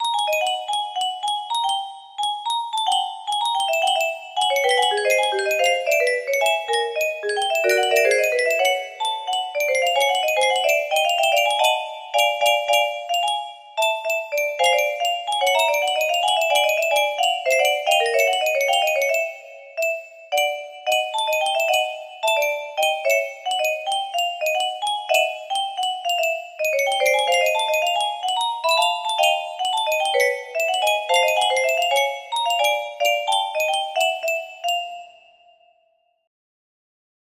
a peace music box melody